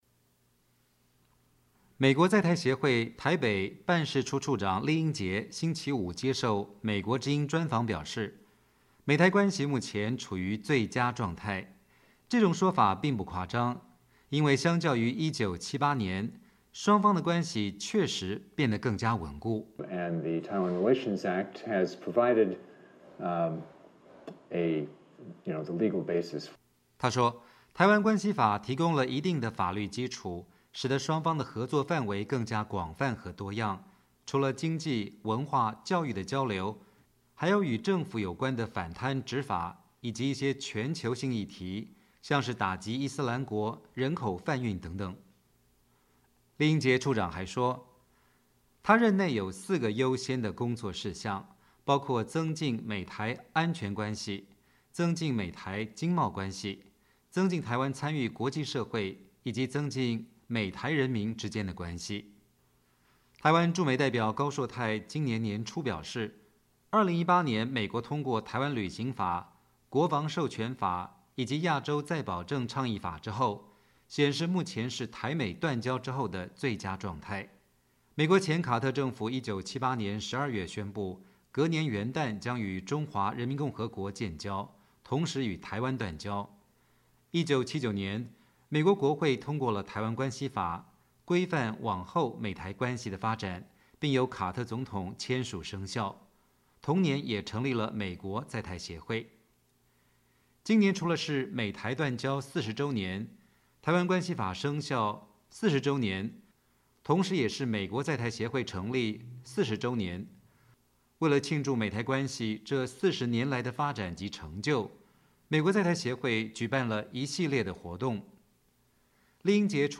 美国在台协会台北办事处处长郦英杰接受美国之音专访(2019年3月15日)